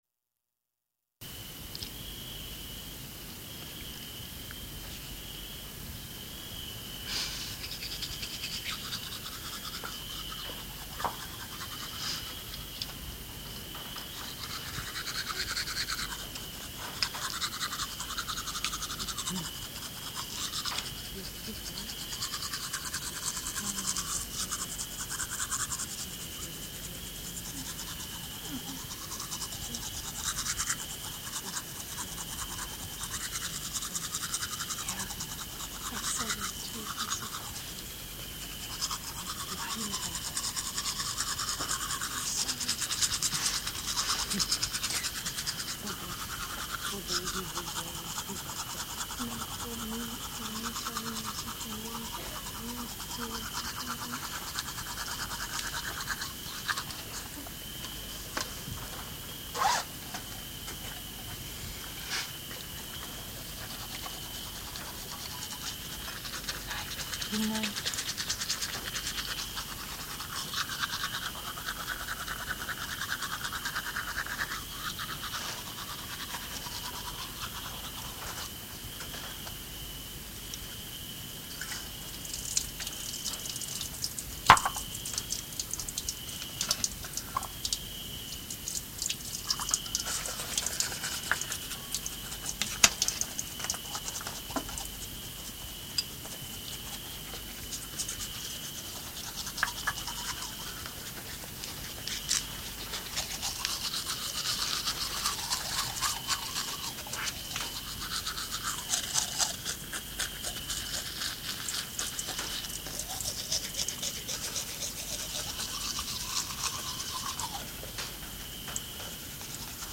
Sonata for Toothbrush, Mumble, Zip Bag, Crickets, Water, and Stone Sink, Number 237